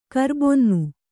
♪ karbonnu